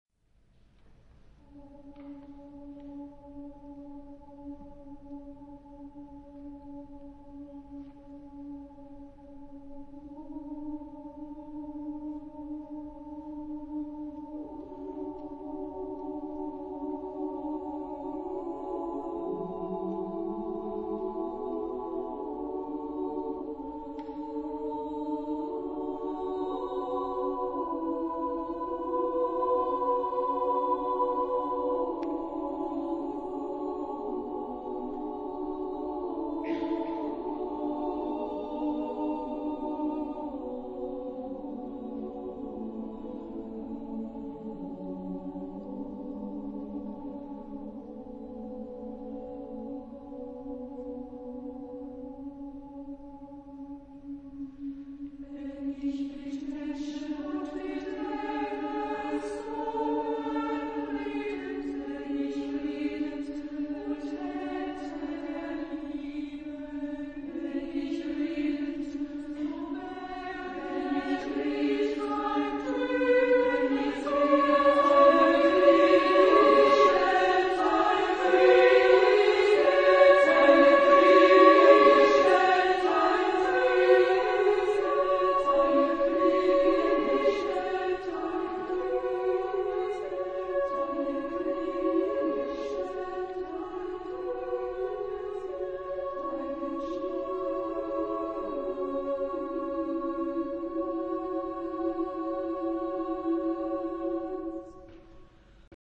Genre-Style-Forme : Motet ; Cycle ; Sacré ; Profane
Type de choeur : SSSSAA  (6 voix égales de femmes )
Instruments : Clochettes de traîneau ; Claves (1)